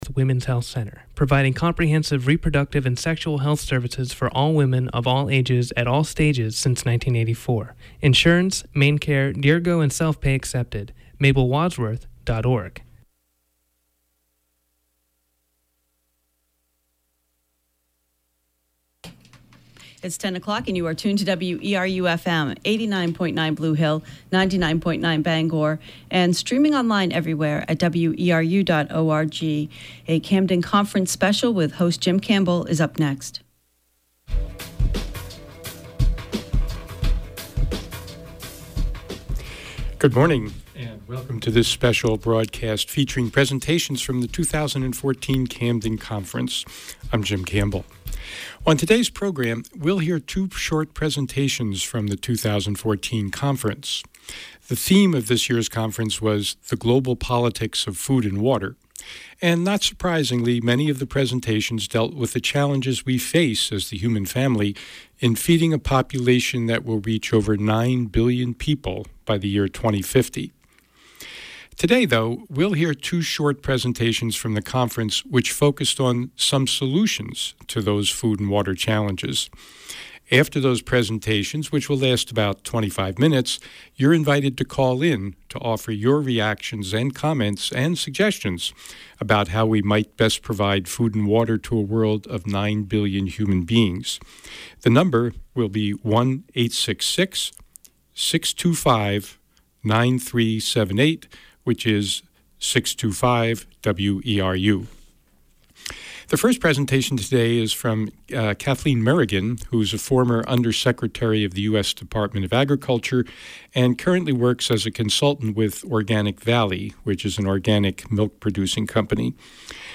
Topic: Two short Presentations from the 2014 Camden Conference; one by Kathleen Merrigen, one by Chellie Pingree